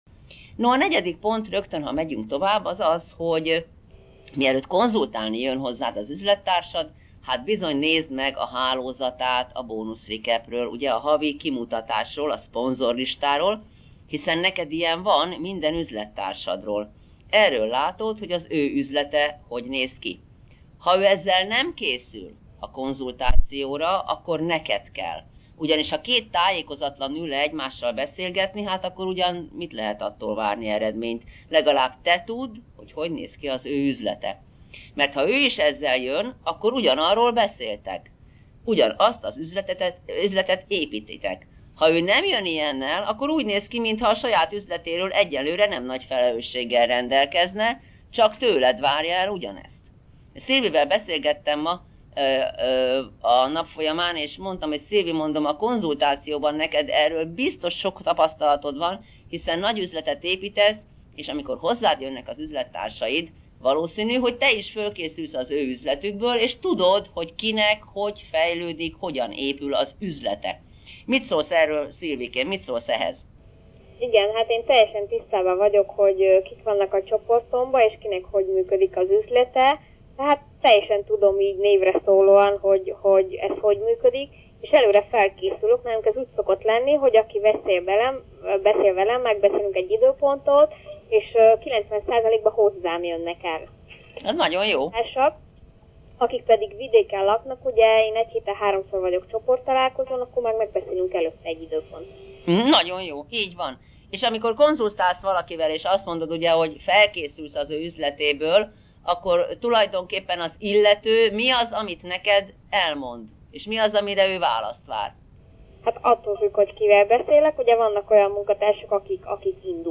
Október végétől a telefonos konferenciák anyagaiból is találhatsz ízelítőt a honlapunkon.
A telefonos konferenciákat minden hónap 1. és 3. szerdáján, 18.25 -től 19.00 –ig tartjuk.
A telefonos konferenciát mi vezetjük az előre felkért üzlettársakkal, a többiek hallgatják.